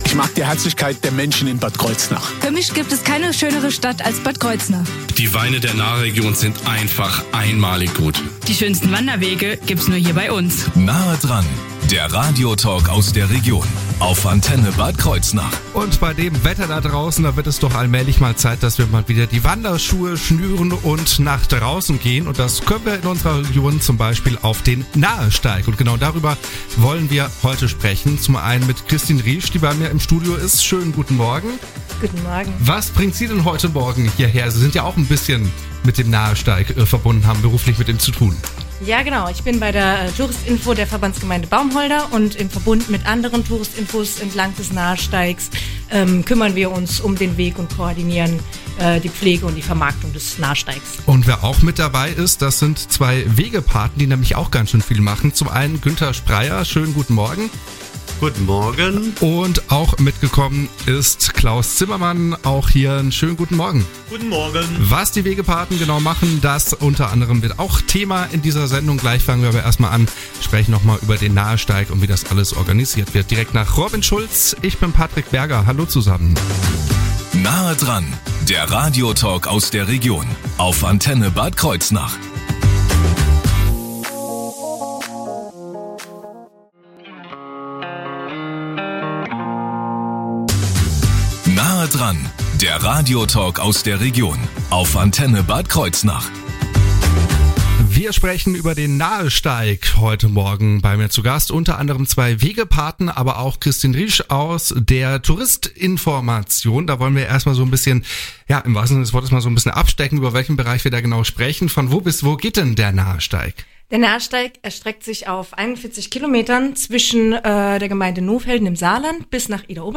Der Heimat- und Verschönerungsverein ist am Dienstag, 8.04.2025 zu Gast bei Radio Antenne Bad Kreuznach.
In der Sendung „Nahe dran“ von 8:30 bis 9:30 Uhr wird es immer mal wieder Interviews mit dem Heimat- und Verschönerungsverein geben.